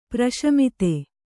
♪ praśamite